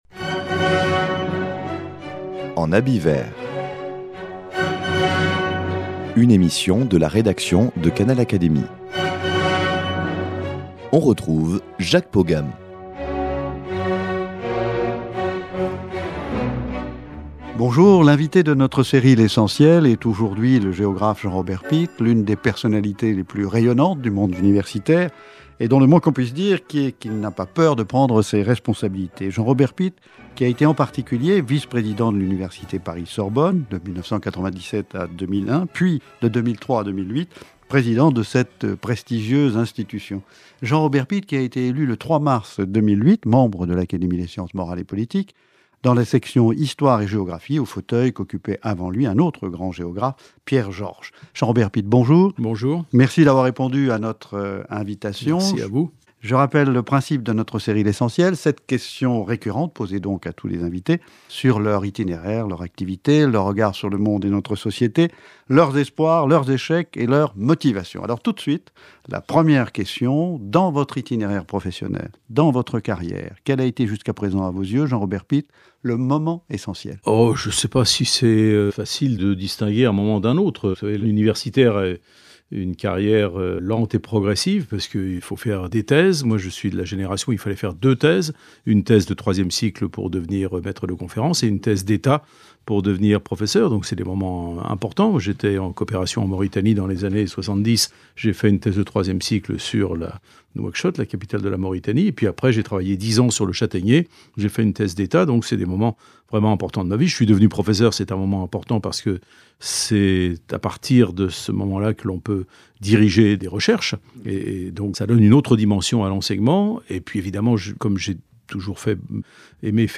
Il répond aux sept questions sur son itinéraire, son activité, son regard sur le monde et notre société, ses espoirs, échecs et motivations !